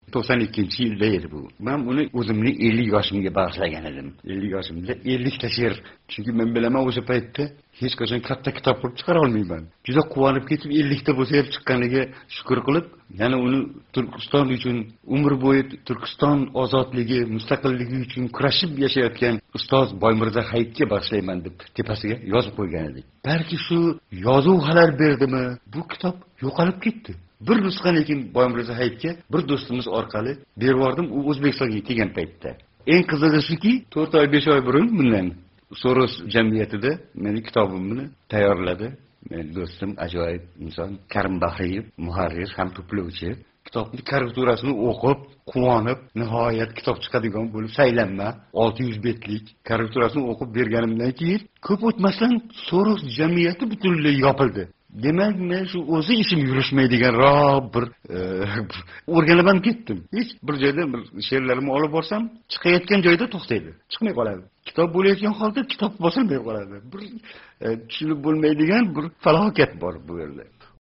Ҳаëтлигида Озодлик билан суҳбатлашган Рауф Парфи¸ китоб чиқариш ëки ижоди ҳақида маданий тадбир ўтказиш режасини ҳамиша "тушуниб бўлмайдиган фалокат" таъқиб қилиши ҳақида изтироб ва кулги билан гапирган эди: